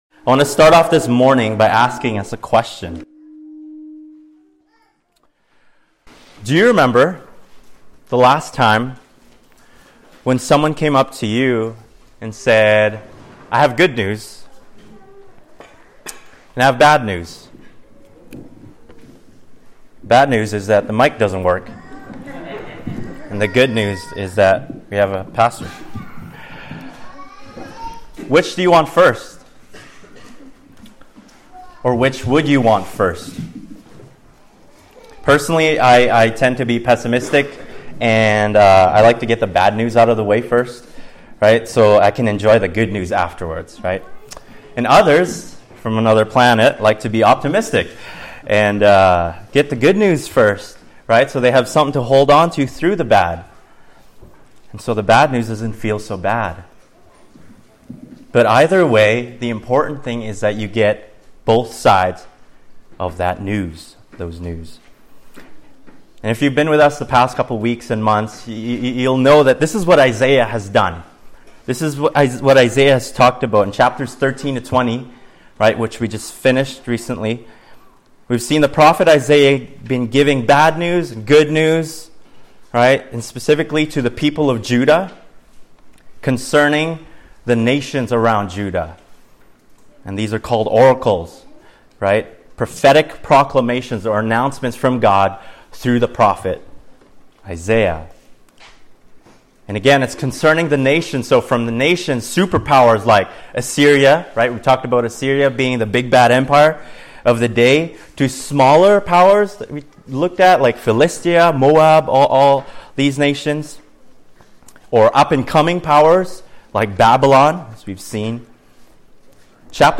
Service Type: Latest Sermon